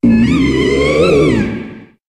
Cri de Banshitrouye dans Pokémon HOME.